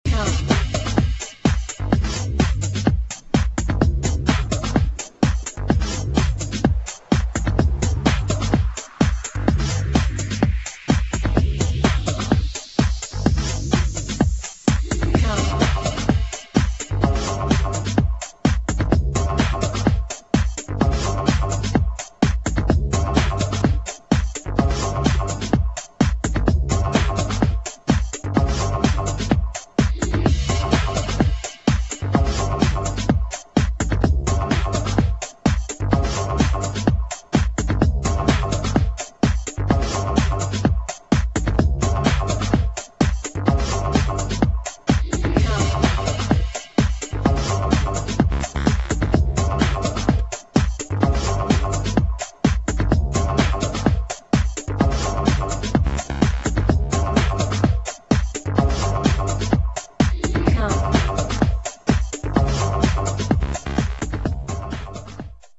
[ TECHNO | PROGRESSIVE HOUSE ]